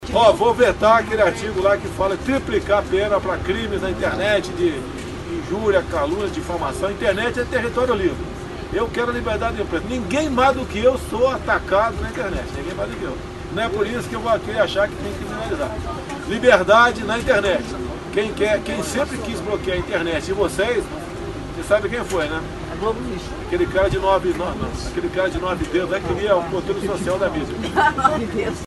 Afirmação foi feita na manhã deste domingo, no Palácio da Alvorada.